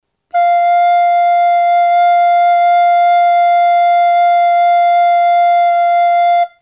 A nota FA.
Nota Fa